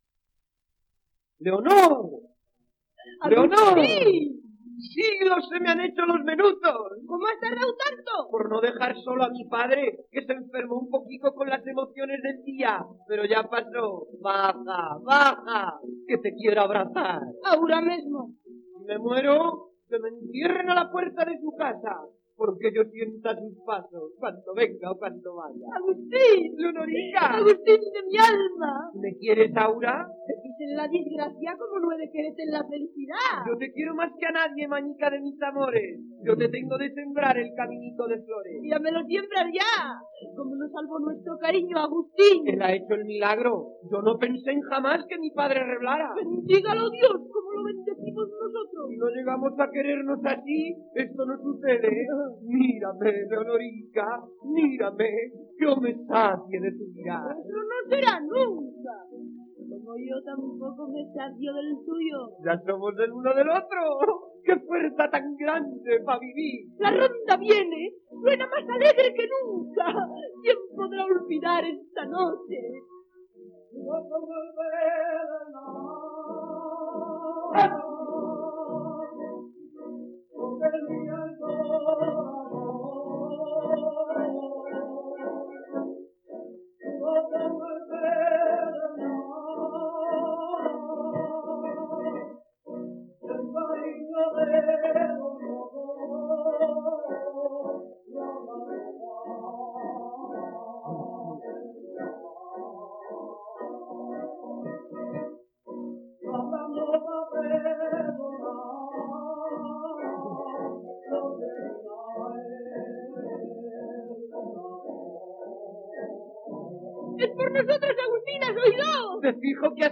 Rondalla: Escena final (sonido remasterizado)
6 discos : 78 rpm ; 25 cm Intérprete